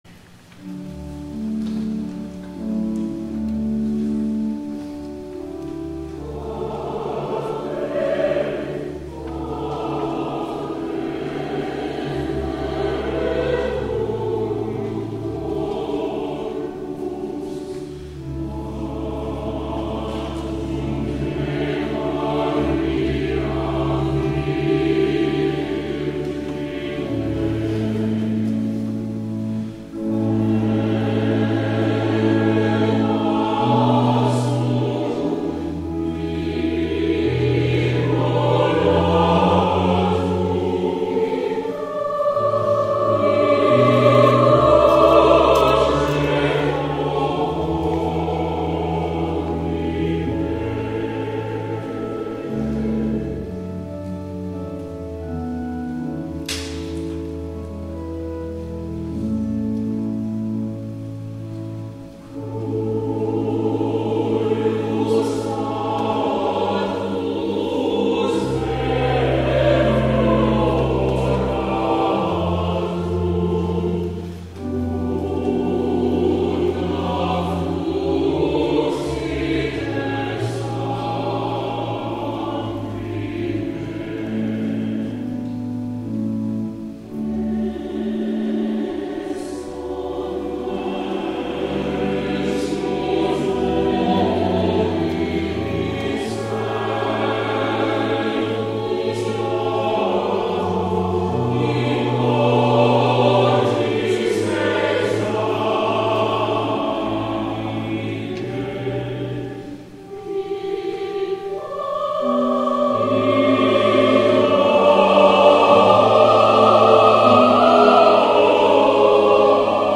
THE ANTHEM